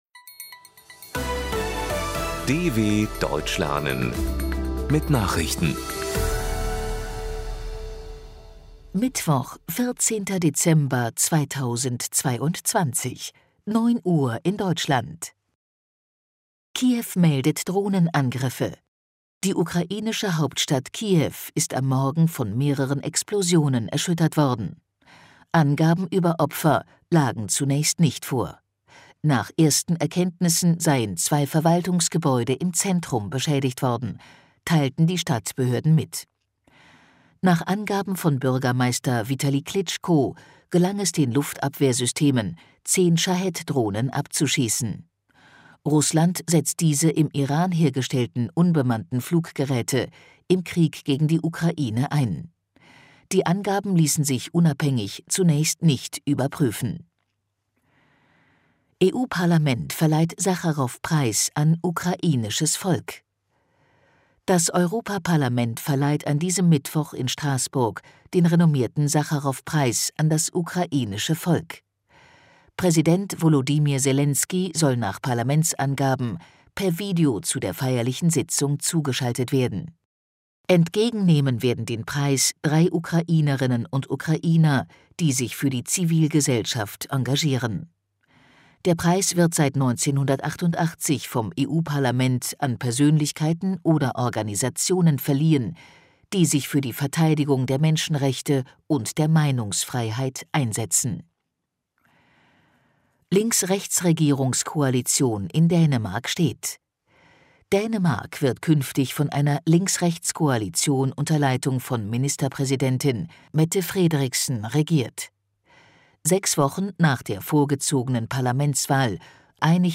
14.12.2022 – Langsam gesprochene Nachrichten
Trainiere dein Hörverstehen mit den Nachrichten der Deutschen Welle von Mittwoch – als Text und als verständlich gesprochene Audio-Datei.